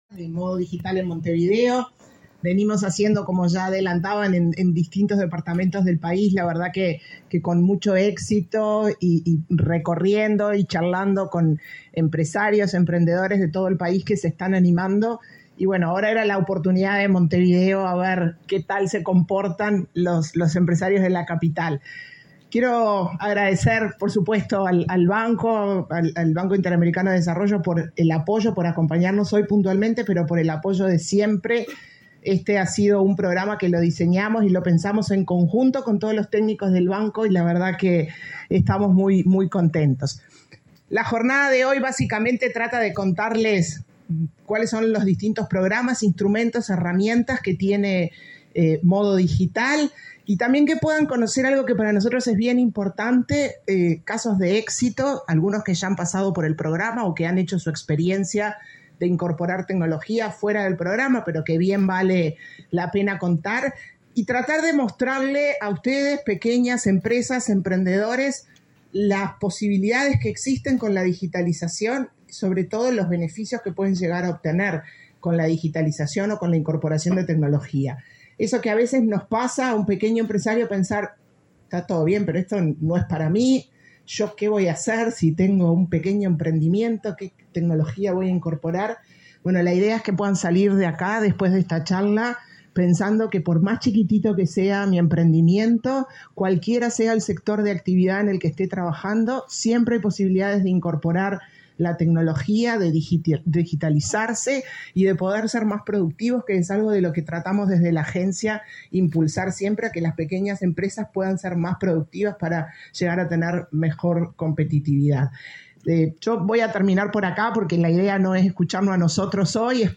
Palabras de la presidenta de la ANDE, Carmen Sánchez
La Agencia Nacional de Desarrollo (ANDE), presentó, este lunes 31, a través de un ciclo de charlas, su programa Modo Digital, para brindar asistencia técnica y apoyo económico a las micro, pequeñas y medianas empresas a fin de que incorporen la tecnología que permita aumentar su competitividad en el mercado. La titular de la ANDE, Carmen Sánchez, explicó la iniciativa.